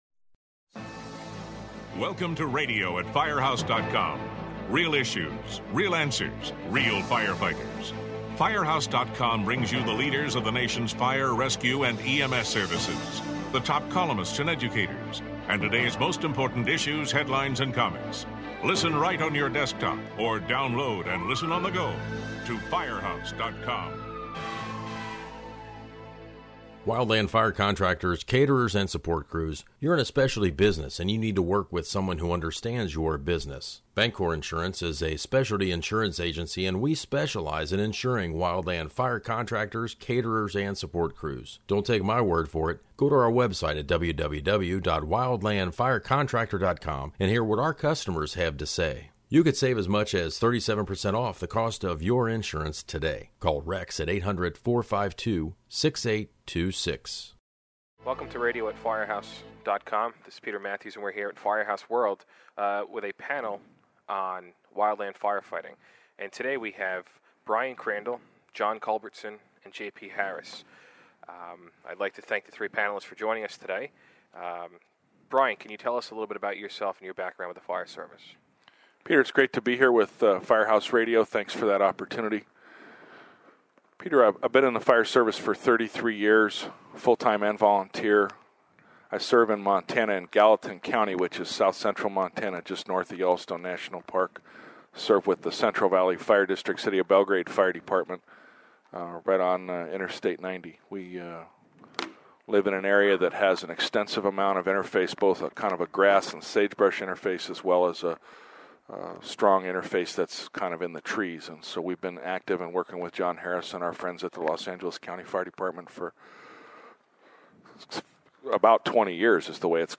Listen as several fire officers talk about wildland and urban interface firefighting operations.
Note: This podcast was recorded at Firehouse World in February.